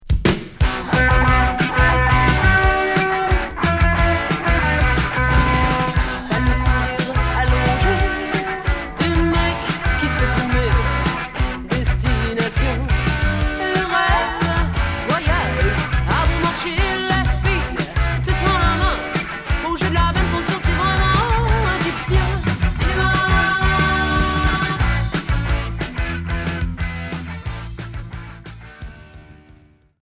Rock Premier 45t retour à l'accueil